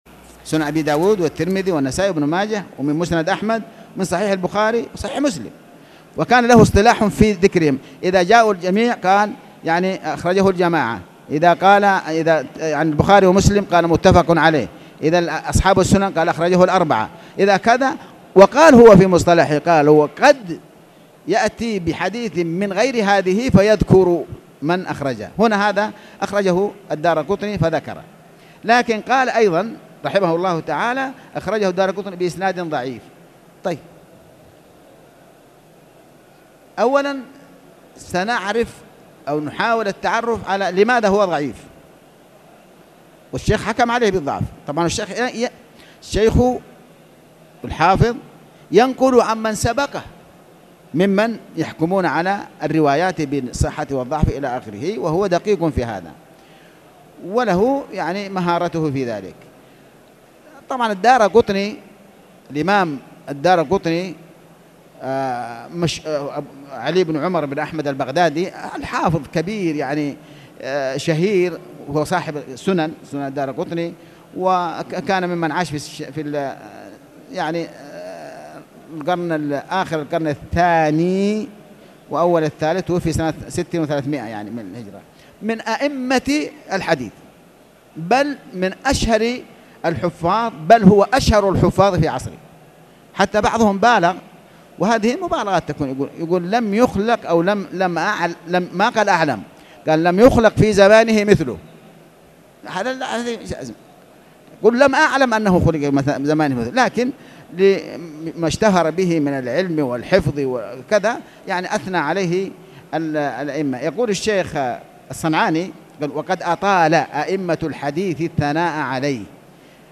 كتاب الطهارة بداية الدرس مقطوع
تاريخ النشر ١٢ جمادى الأولى ١٤٣٨ هـ المكان: المسجد الحرام الشيخ